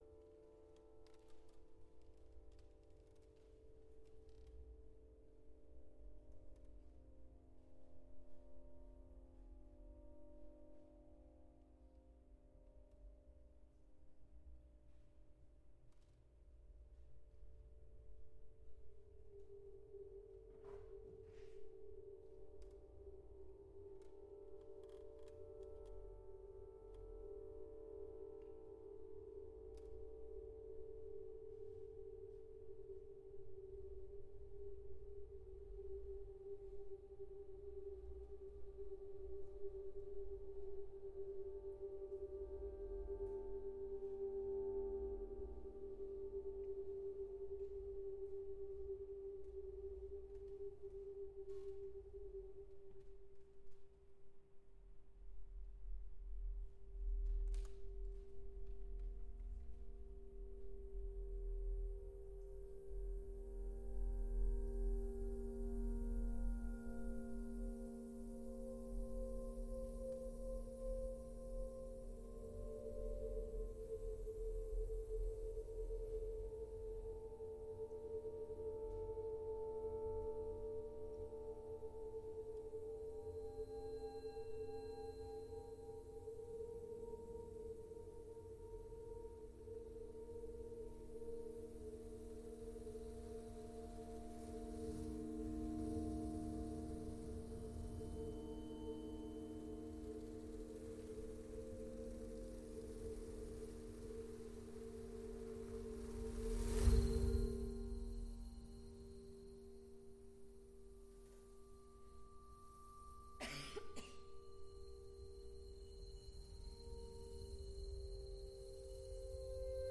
Live from the International Computer Music Conference
Recorded from the Wave Farm Transmit Parter stream.
Its unique interweaving of research paper presentations and concerts of new computer music, refereed by ICMA- approved international panels of experts, creates a vital synthesis of science, technology, and the art of music.